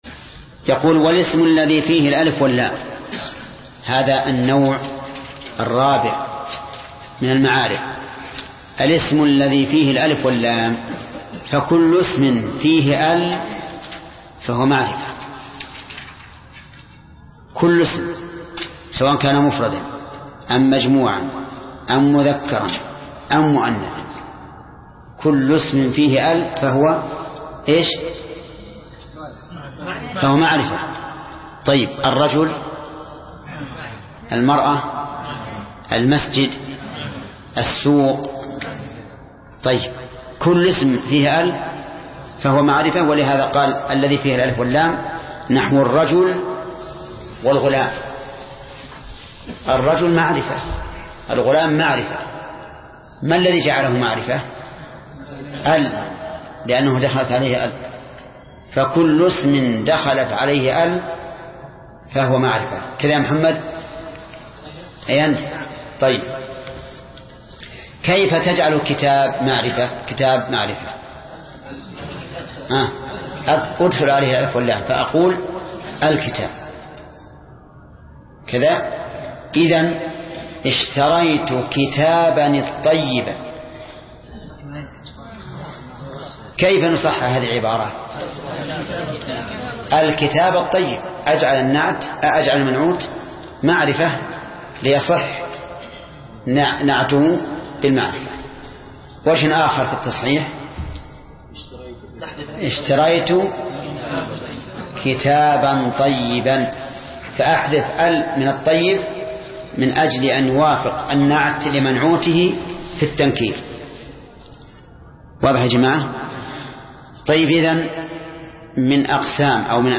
درس (15) : شرح الآجرومية : من صفحة: (306)، قوله: (الرابع: والاسم الذي فيه الألف واللام).، إلى صفحة: (328)، قوله: (والخلاصة).